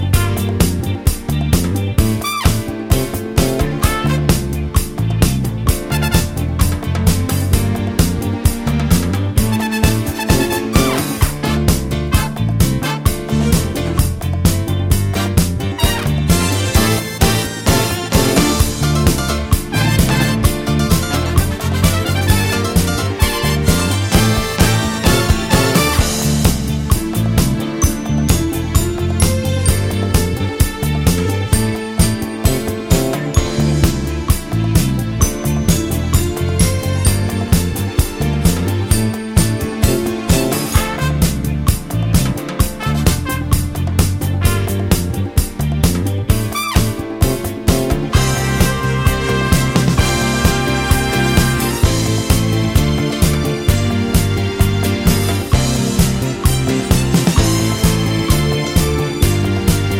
no Backing Vocals Disco 4:56 Buy £1.50